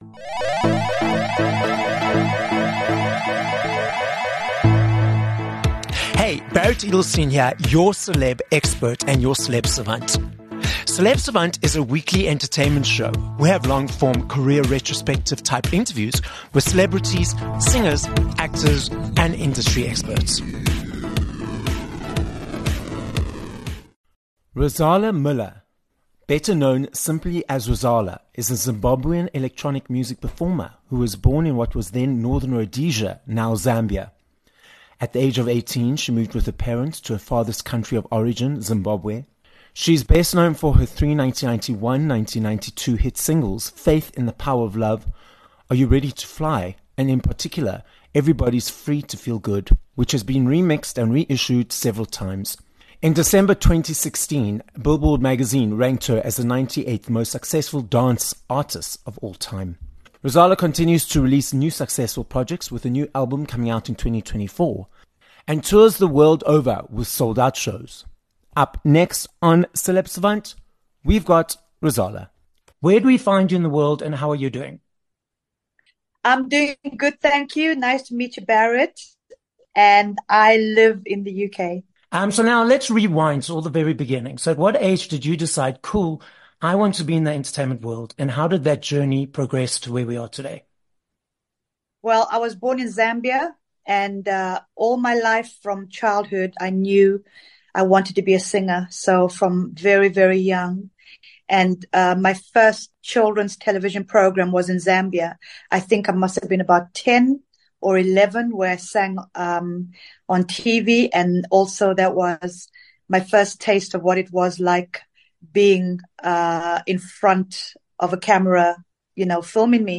9 Jan Interview with Rozalla